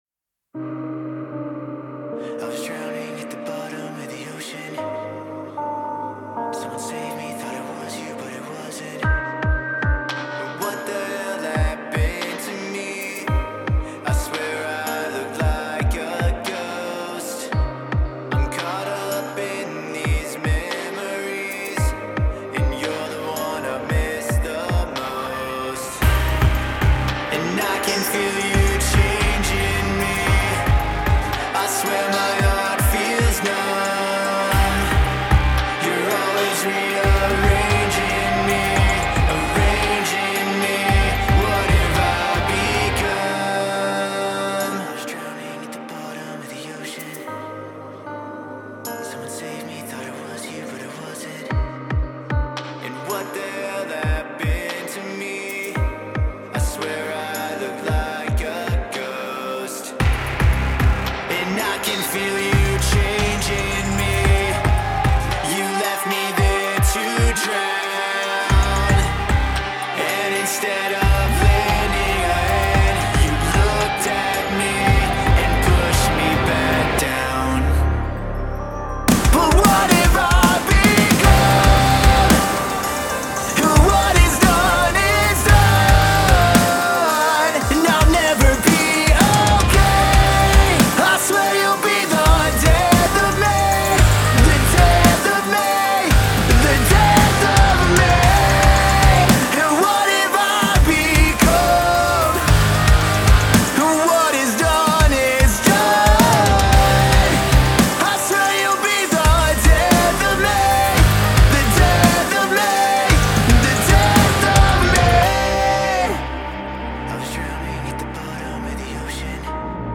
Для мастеринга использую bx_mastering studio.